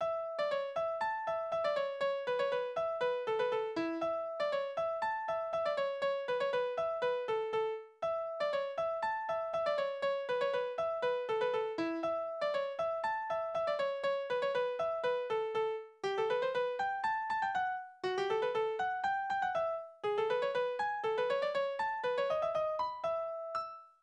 Hamburger Tanzverse: Kreuzpolka Tonart: A-Dur, E-Dur Taktart: 2/4 Tonumfang: zwei Oktaven
Instrumentalstück